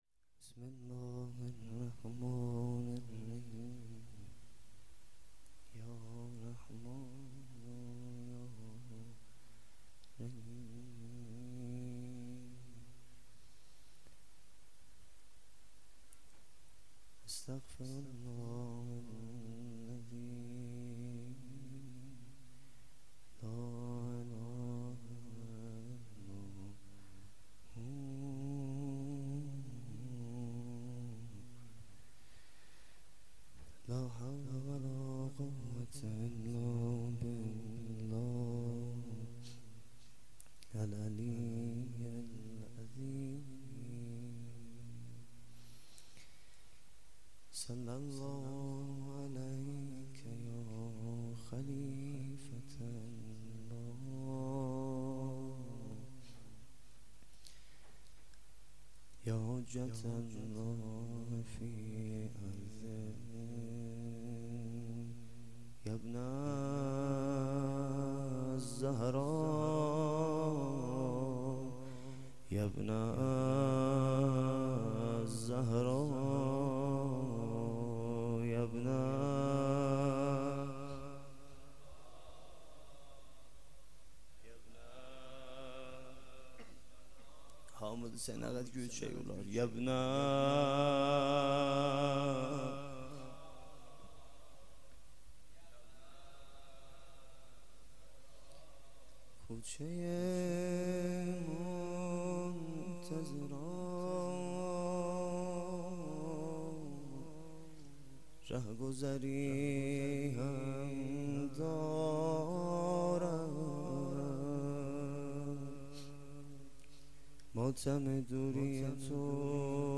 0 0 روضه
مراسم هفتگی